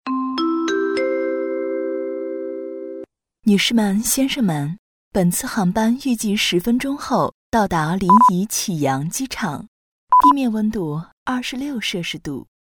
女267-温馨提示—机场播报
女267专题广告解说彩铃 v267
女267-温馨提示-机场播报.mp3